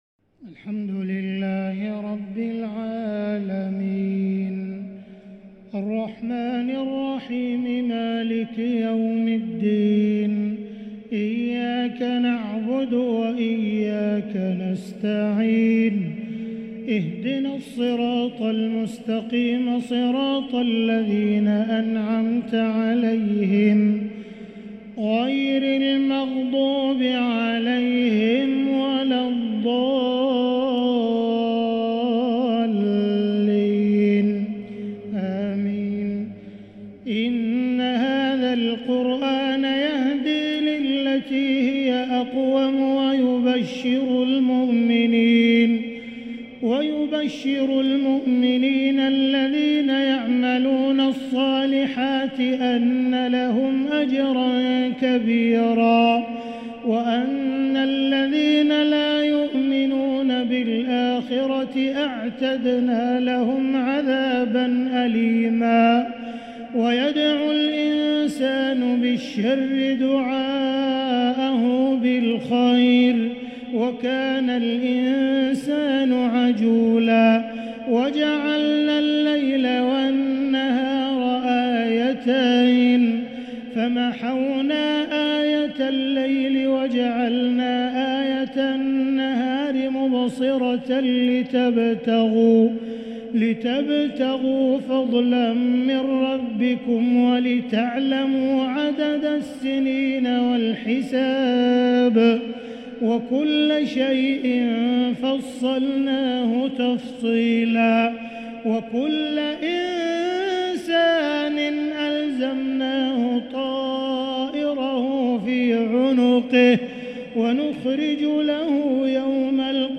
تراويح ليلة 19 رمضان 1444هـ من سورة الإسراء (9-52) | taraweeh 19 th niqht Ramadan1444H Surah Al-Israa > تراويح الحرم المكي عام 1444 🕋 > التراويح - تلاوات الحرمين